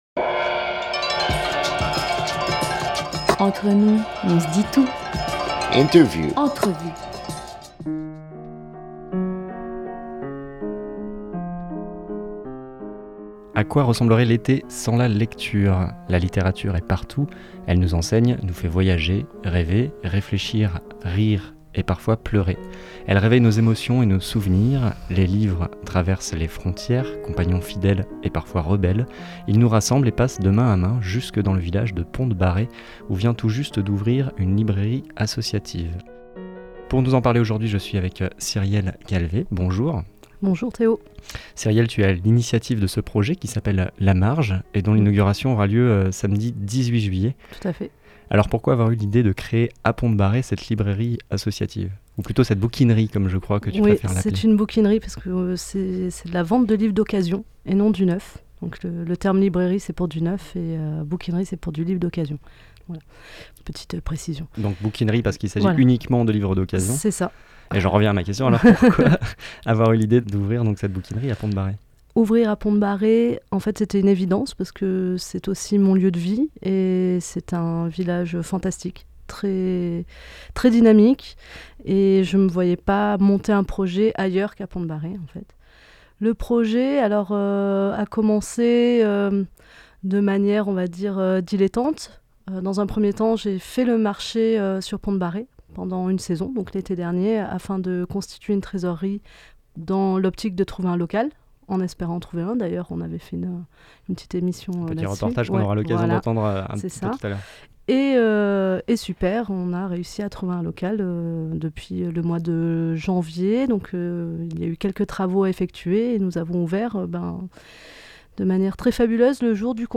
20 juillet 2020 10:16 | Interview